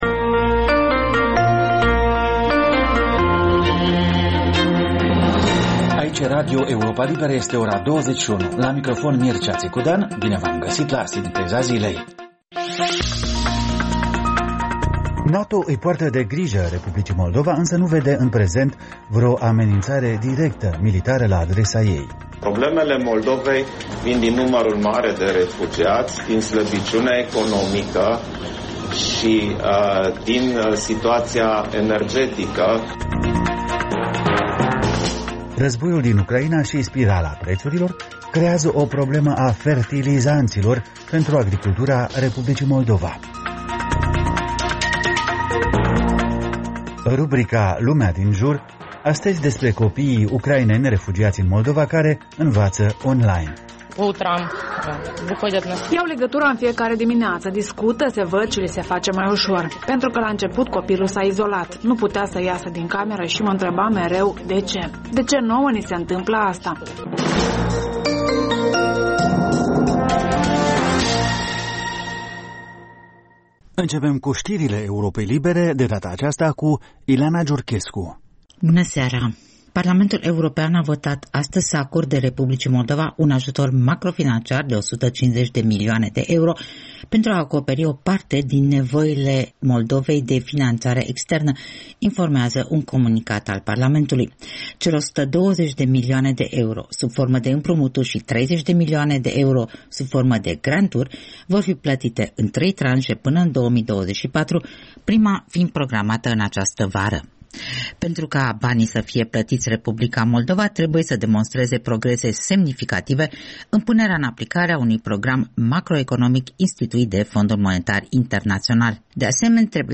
Programul de seară al Europei Libere. Ştiri, interviuri, analize şi comentarii.